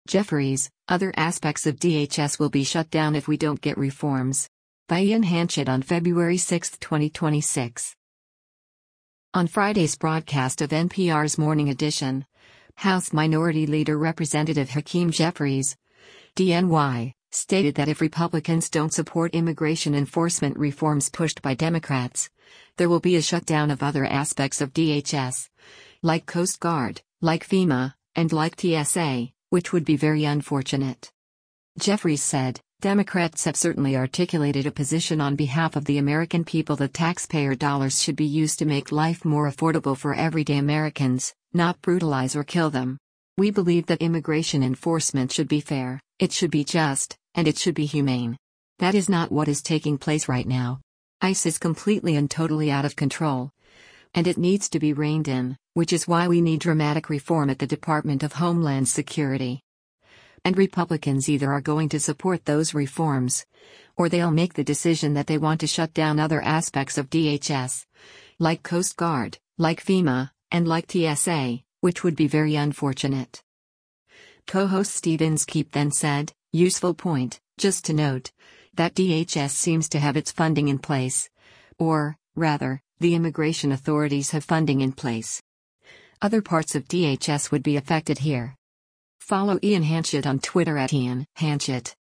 On Friday’s broadcast of NPR’s “Morning Edition,” House Minority Leader Rep. Hakeem Jeffries (D-NY) stated that if Republicans don’t support immigration enforcement reforms pushed by Democrats, there will be a shutdown of “other aspects of DHS, like Coast Guard, like FEMA, and like TSA, which would be very unfortunate.”